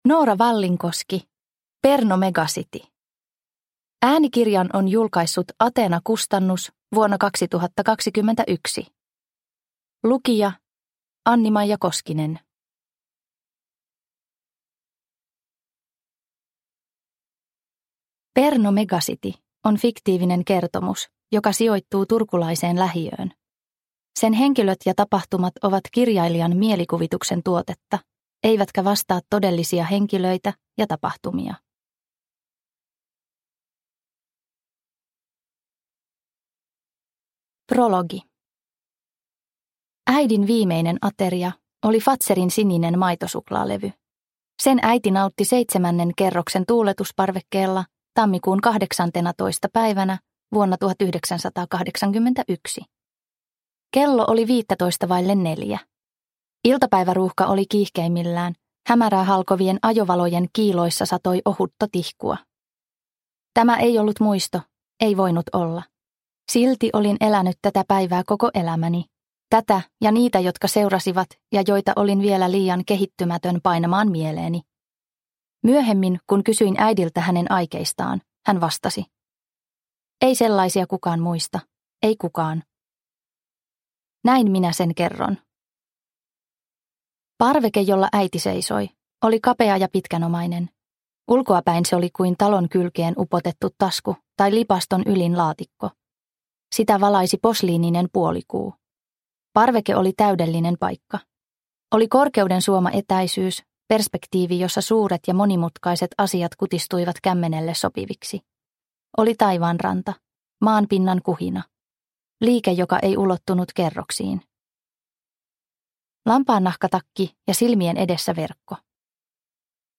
Perno Mega City – Ljudbok – Laddas ner